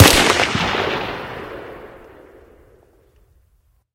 svd_fire_3p.ogg